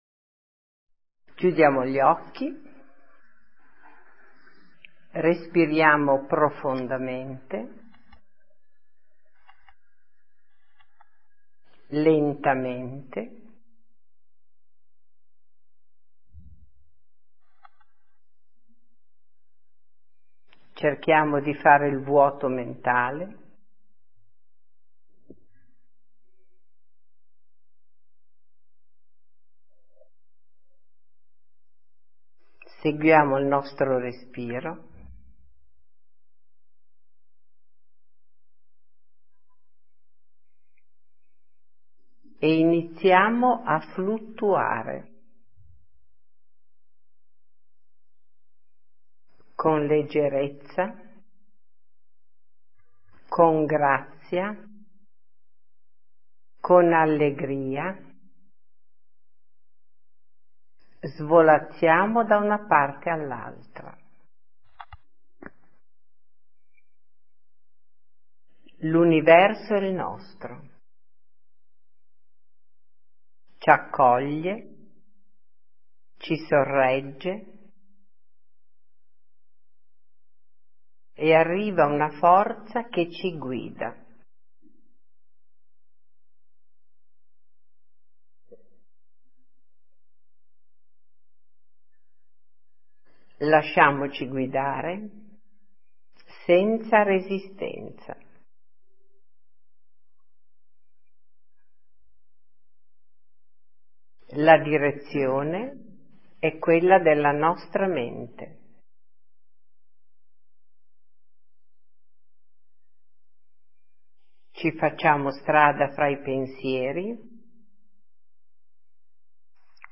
Esercizio per la Leggerezza – meditazione
Esercizio-per-la-Leggerezza-meditazione.mp3